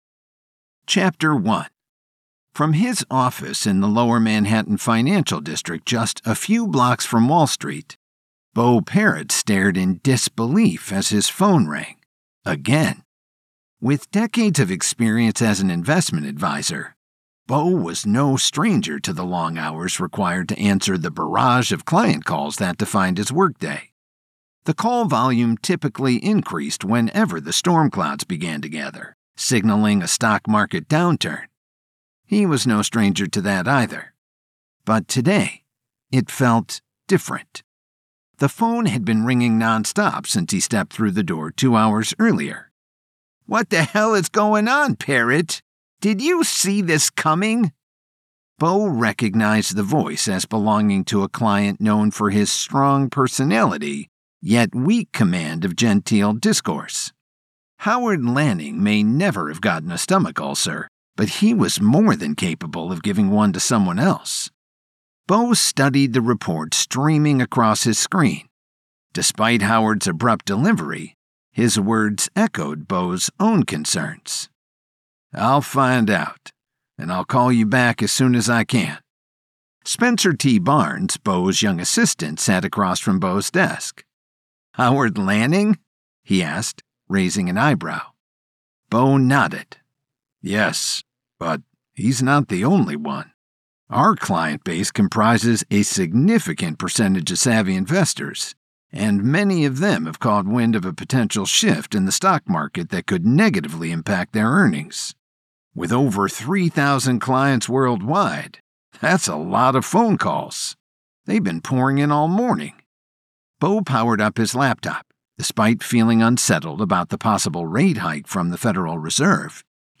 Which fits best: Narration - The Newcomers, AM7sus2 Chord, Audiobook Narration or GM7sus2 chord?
Audiobook Narration